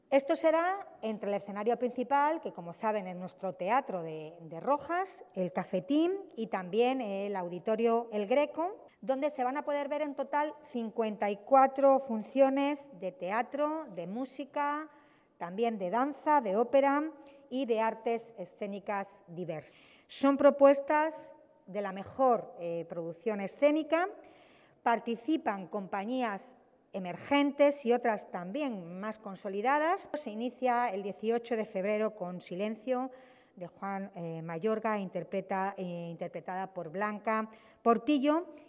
AUDIOS. Milagros Tolón, alcaldesa de Toledo